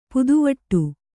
♪ puduvaṭṭu